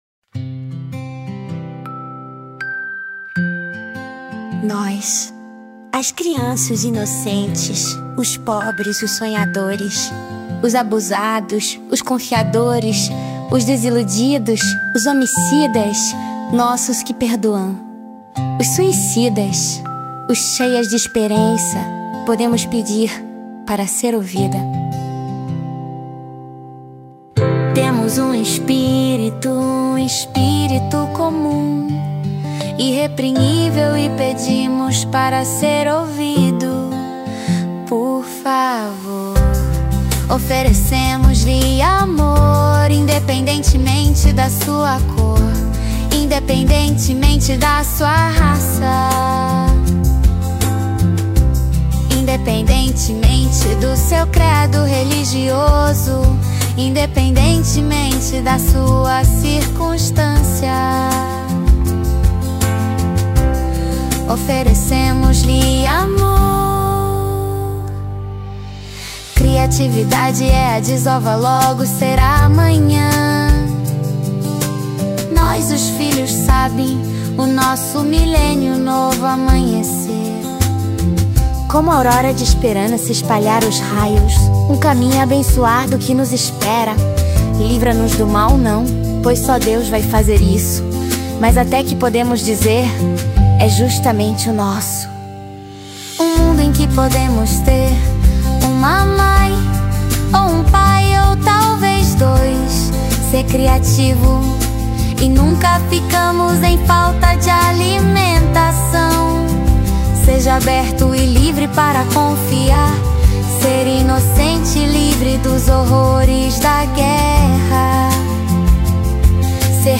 Apart from being recorded in the country of origin of the respective language,  the multiple-language recordings represent Australia’s multicultural community and the voices of Australia’s youth.
TCM_Portuguese-Master.mp3